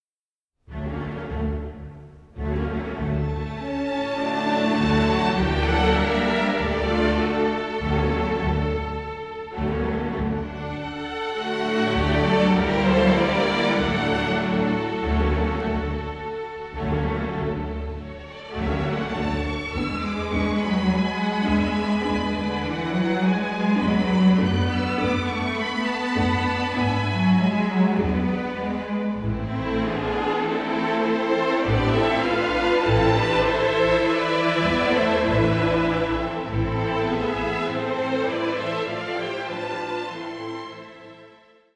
recorded at Abbey Road Studios